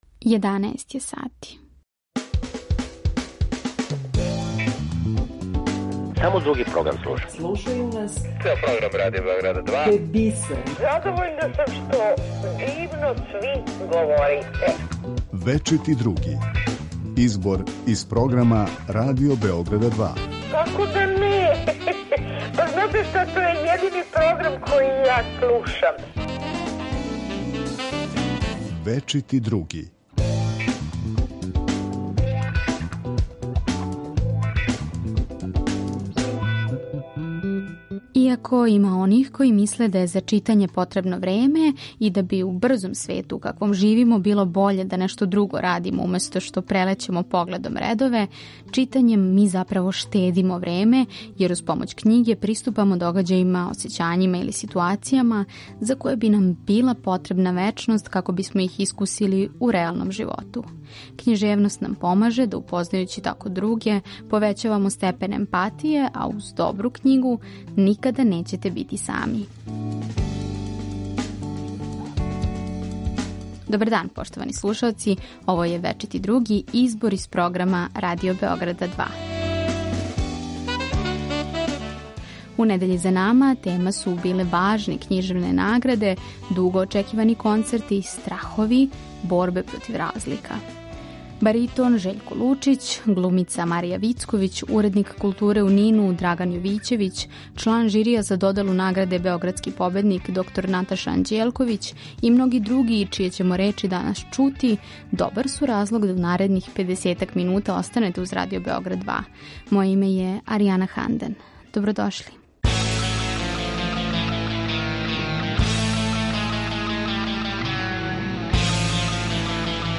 Осим тога, чућемо и вас, поштовани слушаоци, али и друге госте нашег програма. Поред тога што издвајамо делове из прошлонедељног програма, најавићемо и неке од емисија које ћете чути на Радио Београду 2.